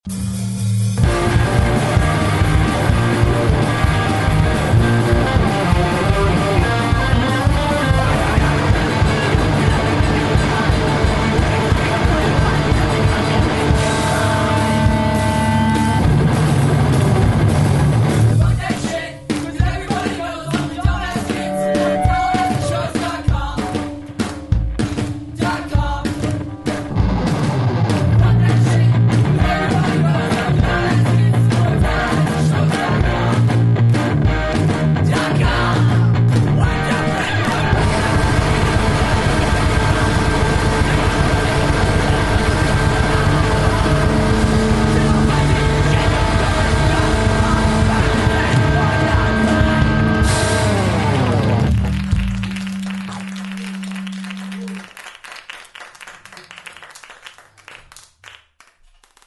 They are a very tongue-in-cheack hardcore band.